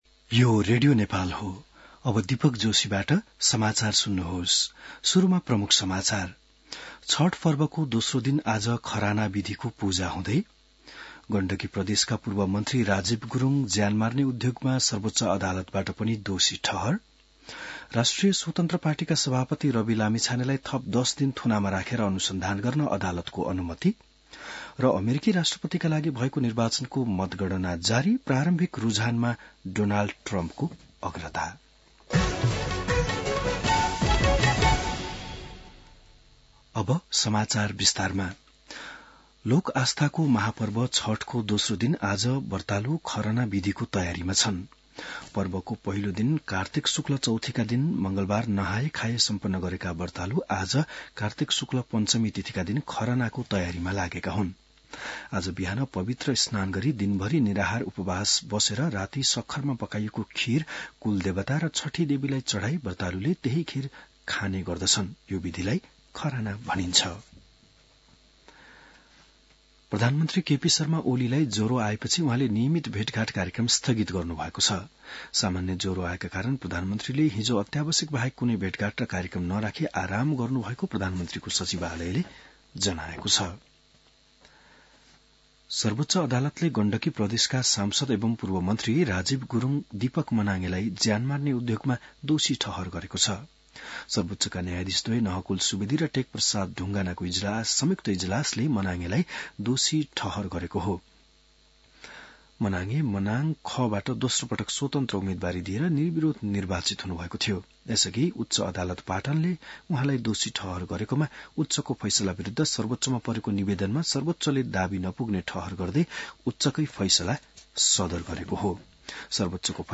बिहान ९ बजेको नेपाली समाचार : २२ कार्तिक , २०८१